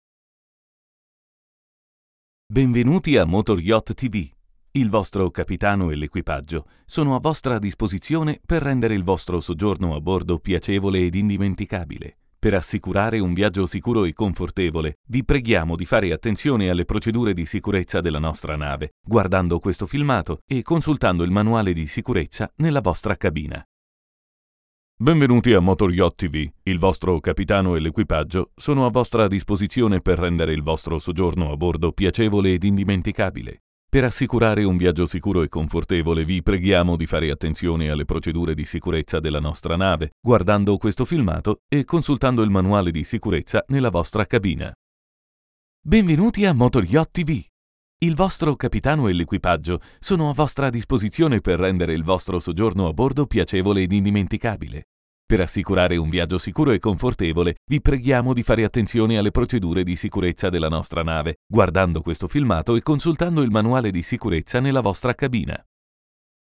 Italian Native Voice Over with perfect pronunciation. Warm silky friendly Versatile Character Native Agreeable Virile Agile Deep Young Adult
Sprechprobe: eLearning (Muttersprache):